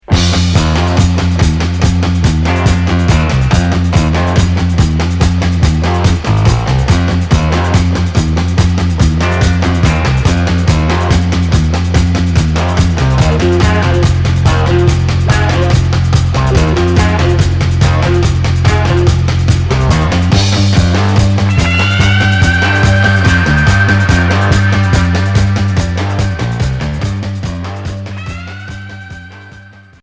Pop rock Unique 45t retour à l'accueil